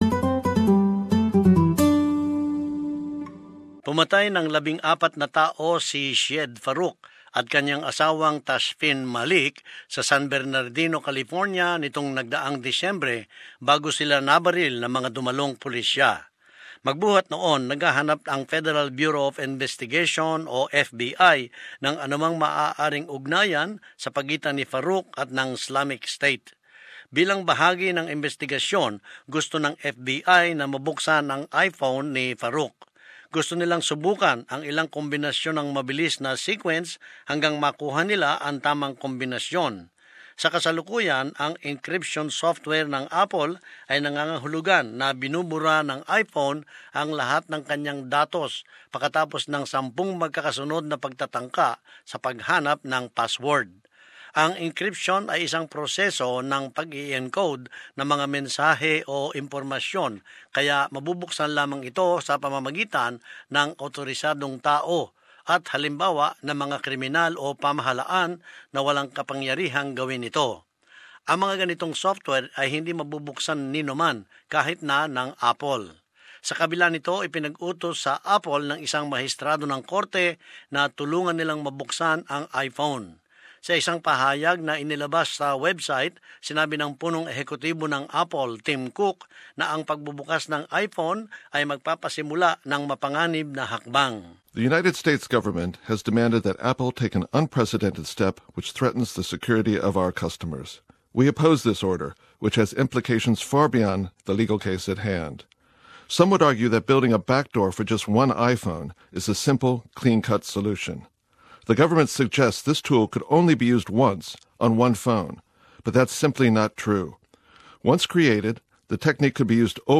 And as this report shows, it has reignited the debate about national security versus privacy concerns.